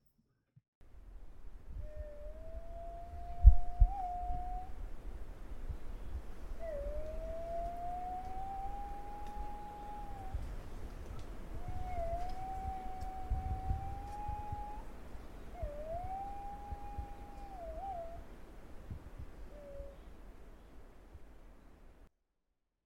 • ほか動物の声（屋久島にて収録）
ズアカアオバト